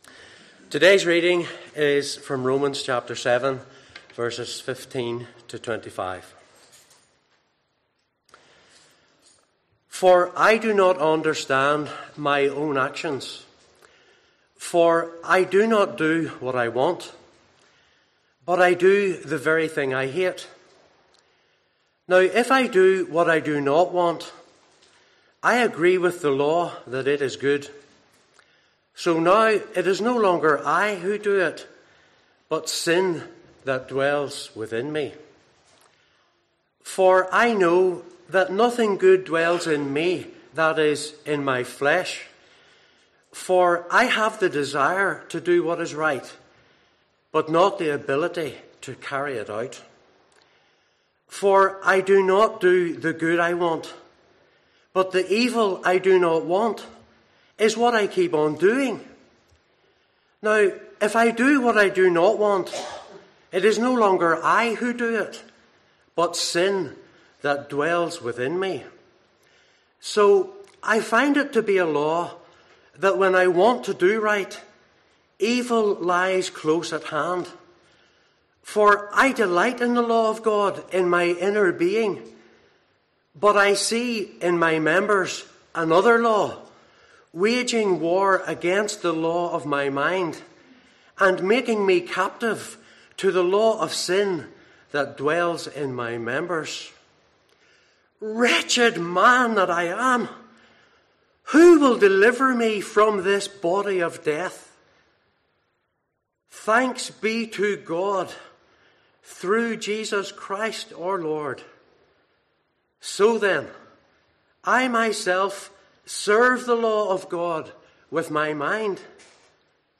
Sermons based on Romans